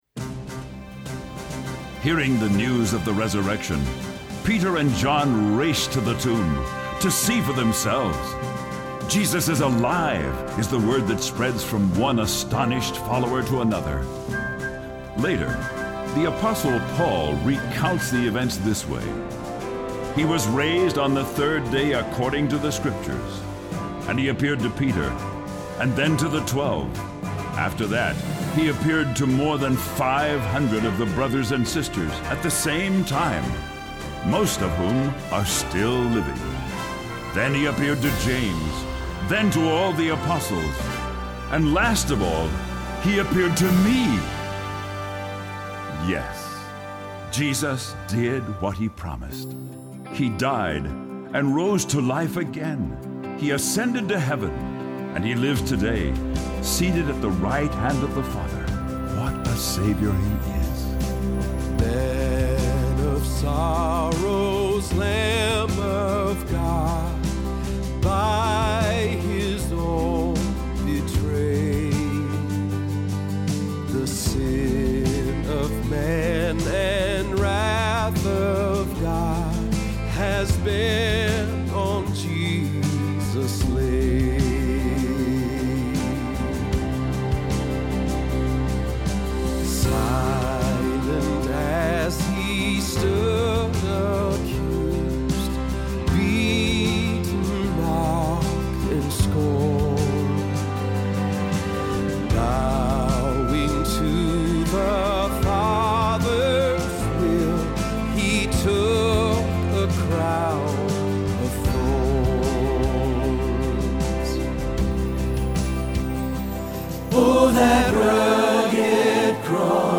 06+Man+Of+Sorrows-Bass.mp3